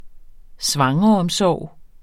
Udtale [ ˈsvɑŋʁʌʌmˌsɒˀw ]